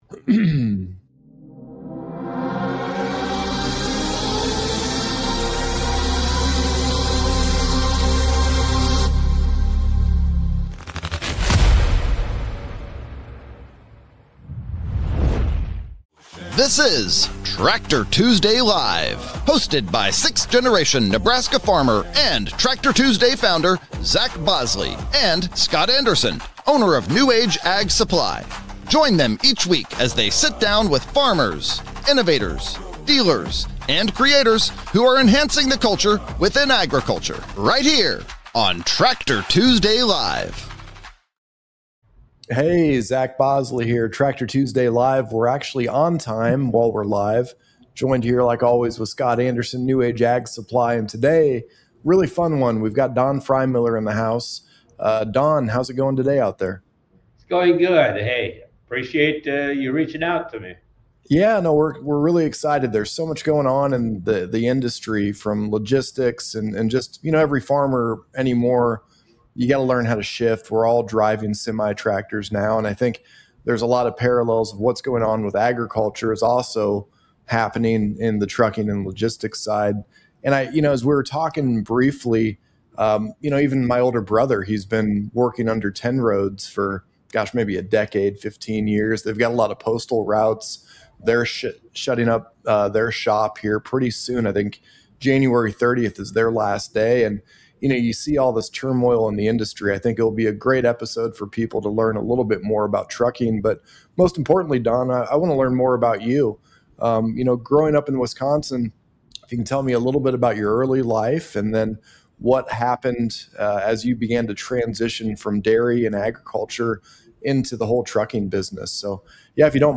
Join us for an in-depth conversation